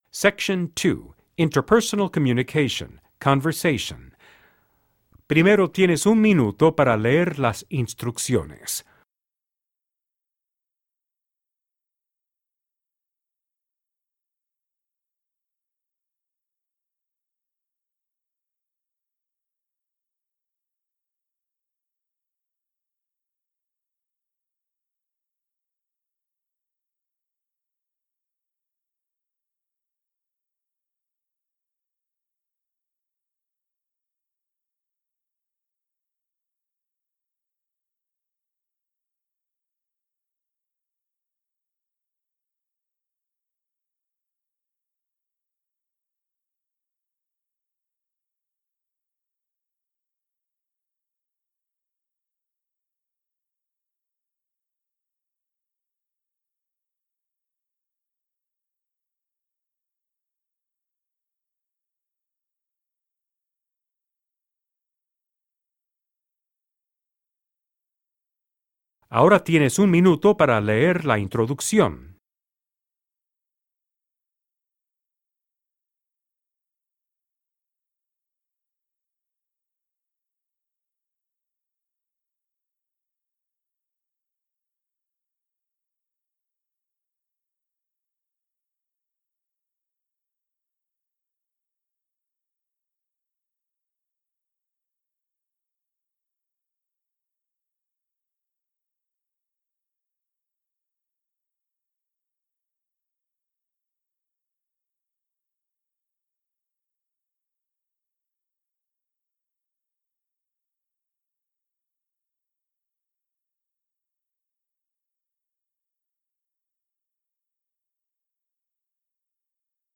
Genre: Alternative.